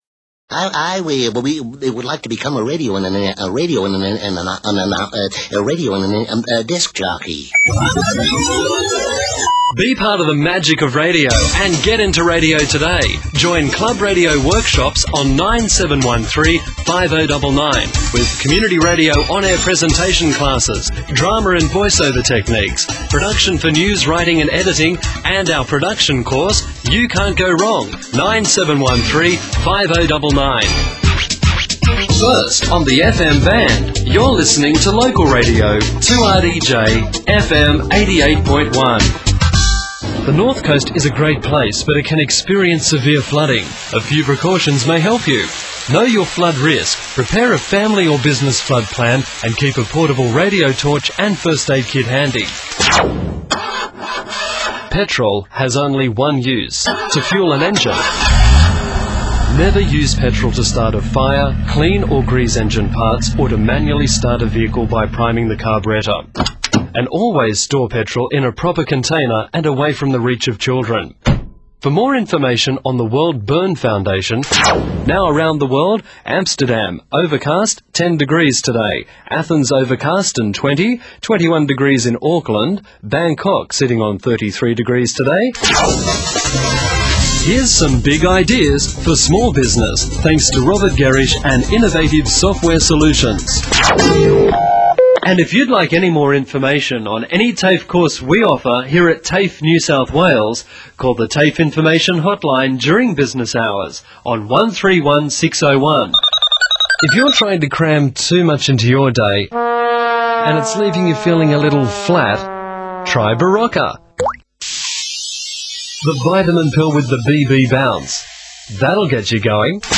promos: Club Radio